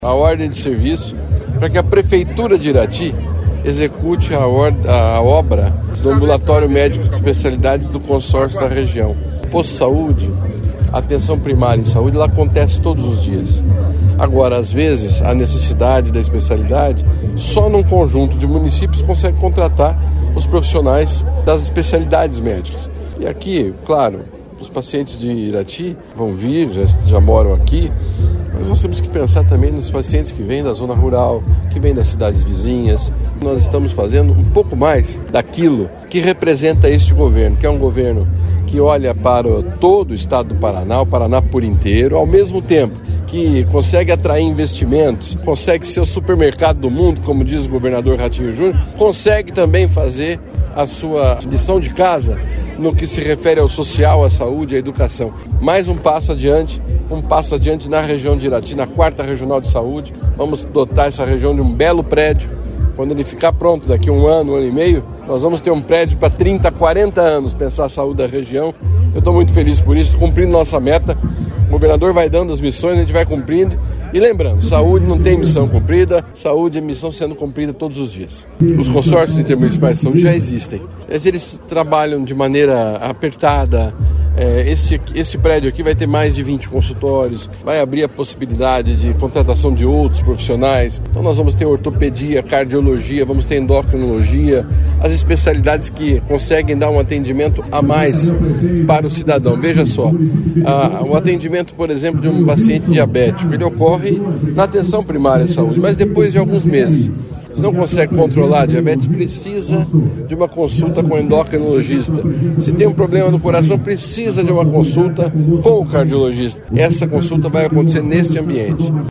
Sonora do secretário da Saúde, Beto Preto, sobre construção do Ambulatório Médico de Especialidades em Irati